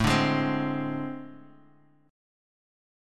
AmM7bb5 chord